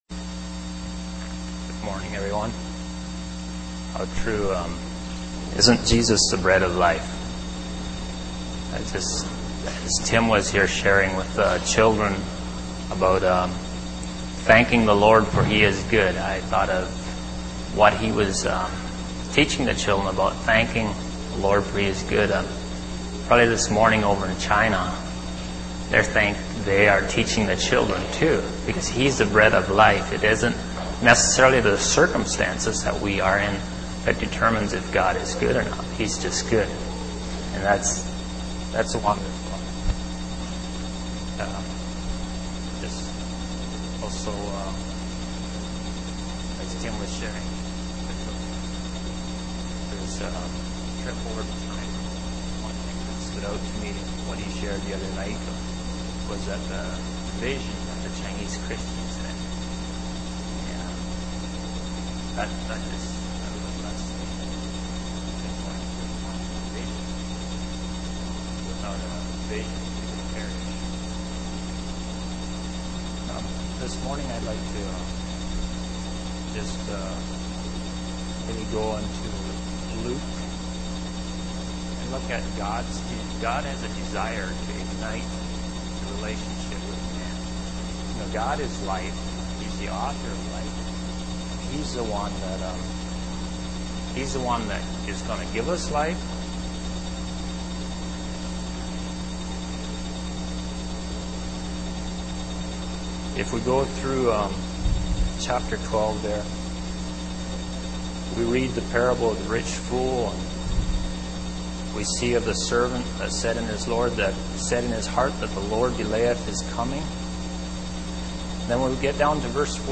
Series: Sunday Morning Sermon Passage: Luke 12:1-59 Service Type